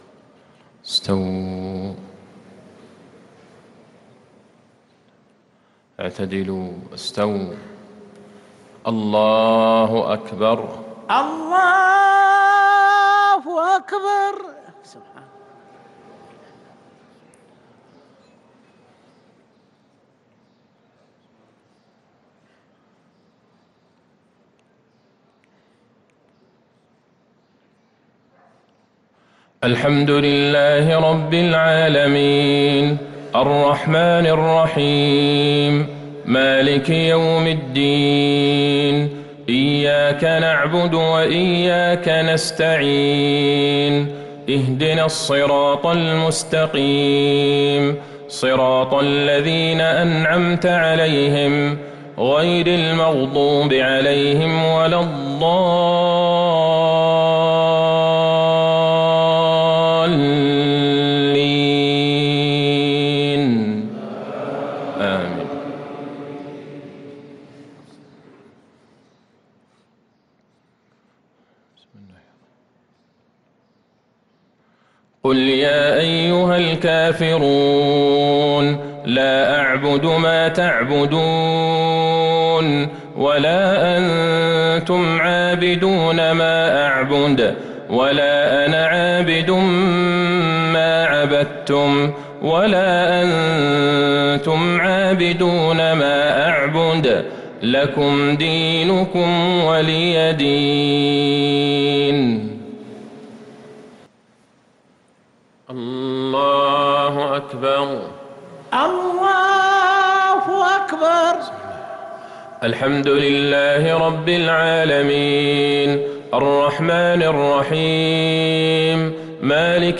صلاة المغرب للقارئ عبدالله البعيجان 29 محرم 1444 هـ
تِلَاوَات الْحَرَمَيْن .